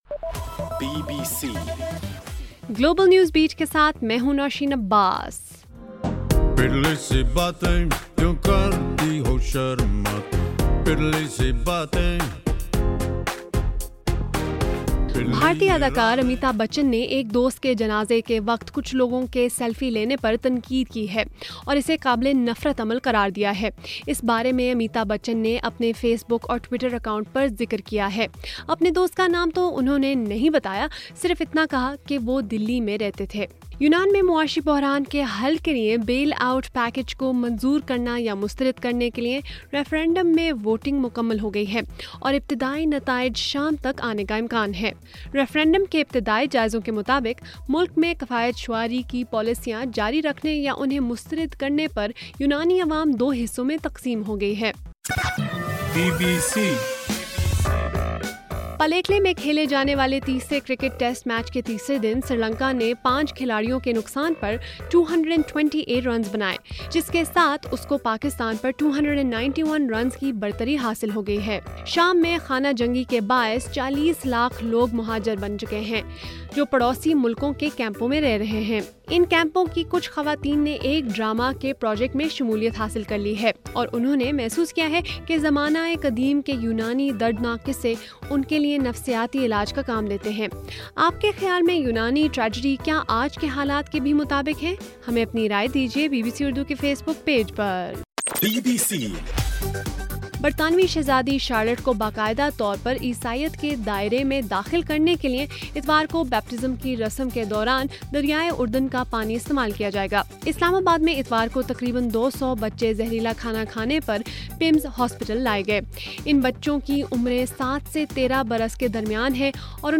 جولائی 5: رات 12 بجے کا گلوبل نیوز بیٹ بُلیٹن